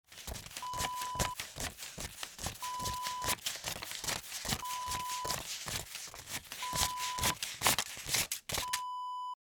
Money: Counting Bills Wav Sound Effect #4
Description: The sound of counting dollar bills (bills fall onto a table)
A beep sound is embedded in the audio preview file but it is not present in the high resolution downloadable wav file.
Keywords: stack, one, five, ten, twenty, hundred, dollar, euro, bank note, bill, bills, hand, cash, money, calculate, count, counting
money-counting-bills-preview-4.mp3